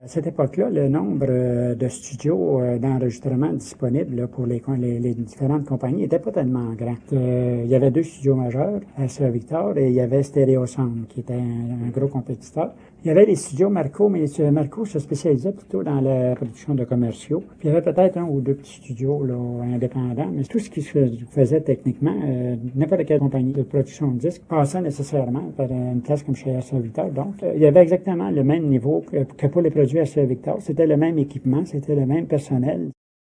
Extraits sonores des invités (Histoire des maisons de disques)